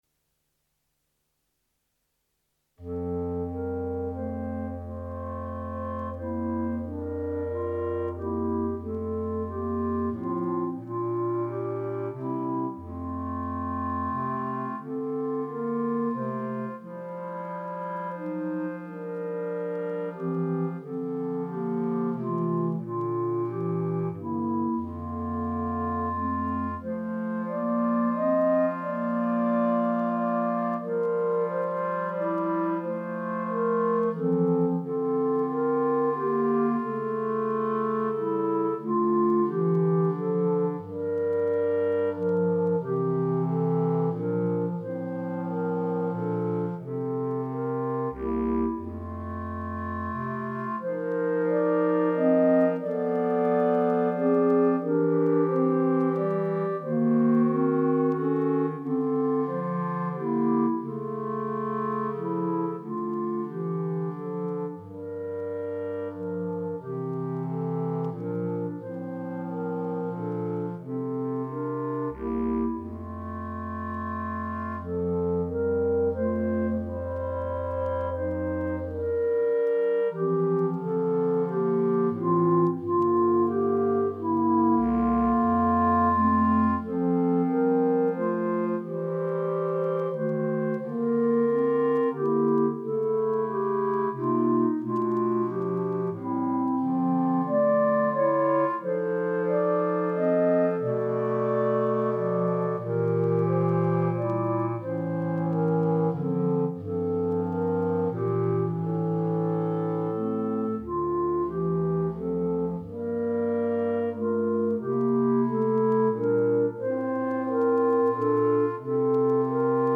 Instrumentation:3 Clarinet, Bass Cl.